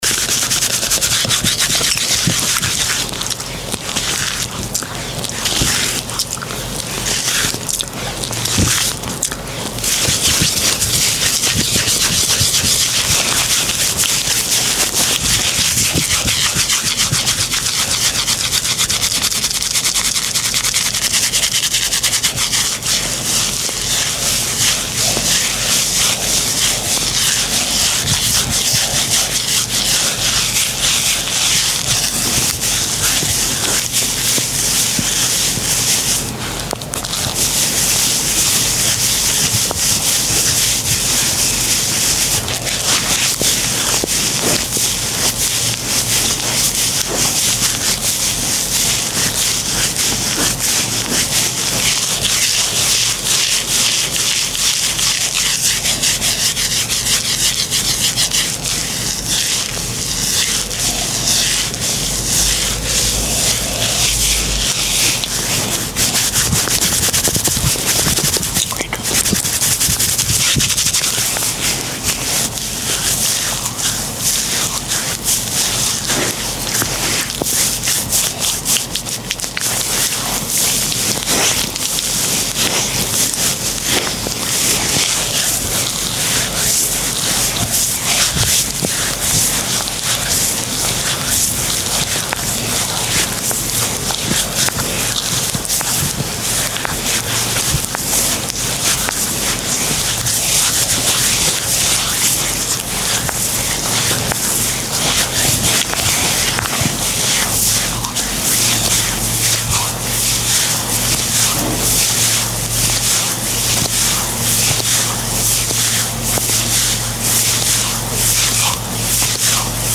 ASMR for sleep and relaxation sound effects free download